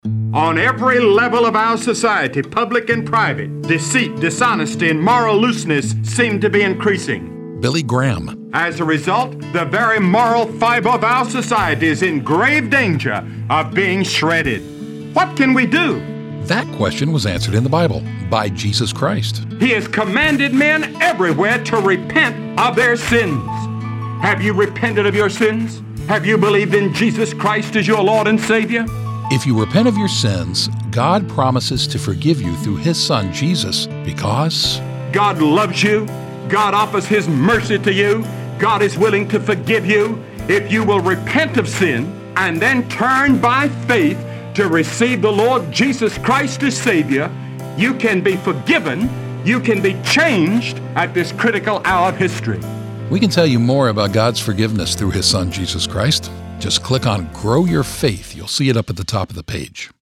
God's Forgiveness: A 60-Second Lesson from Billy Graham